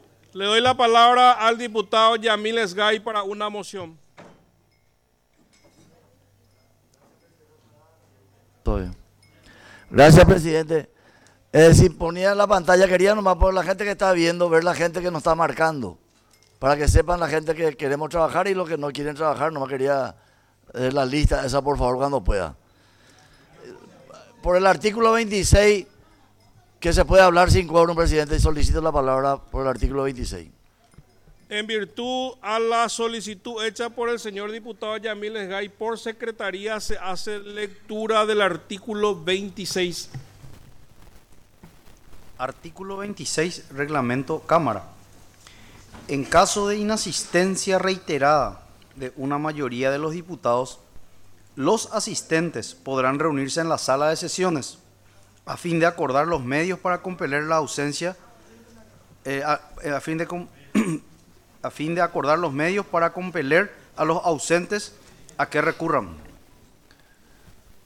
No habiendo quorum, en virtud al artículo 26 del reglamento Cámara los diputados presentes se reunieron en la sala de sesiones para sus intervenciones.